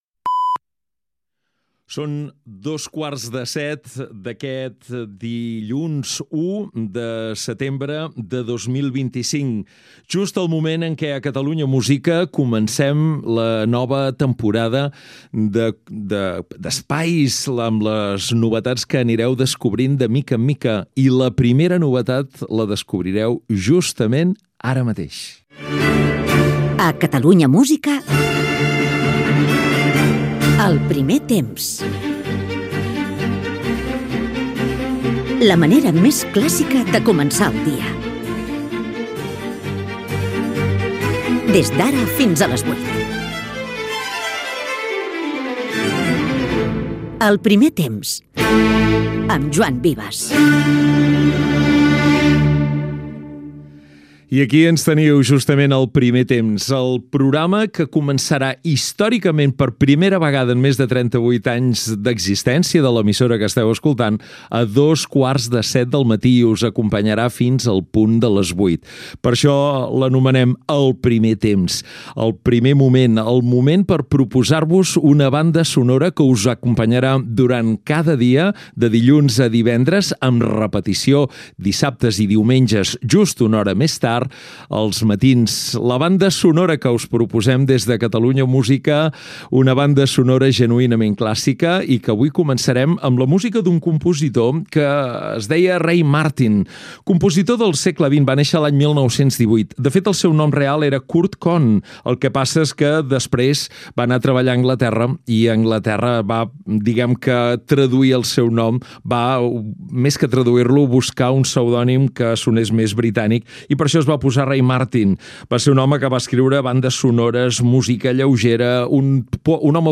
Hora, data, inici del primer programa, en començar la temporada 2025-2026. Careta, comentari sobre el nom del programa i els seus horaris, tema musical
Musical
FM